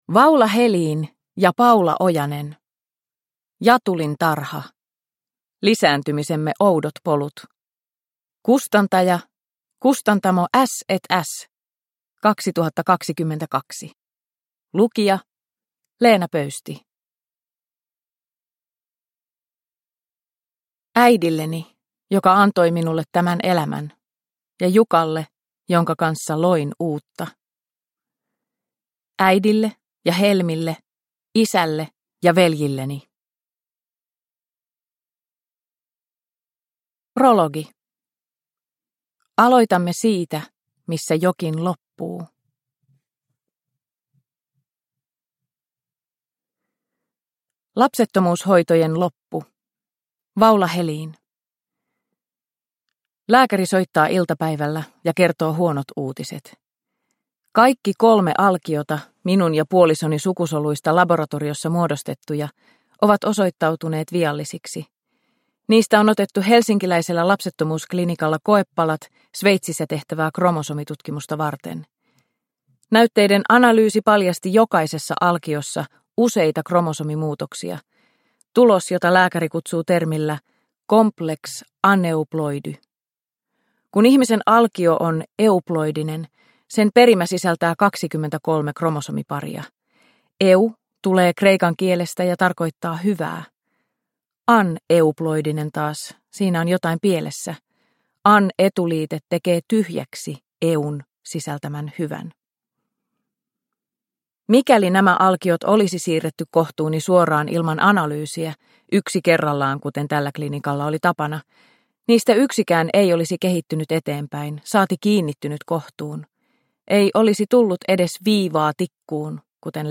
Jatulintarha - Kaksi tositarinaa lapsettomuushoidoista – Ljudbok – Laddas ner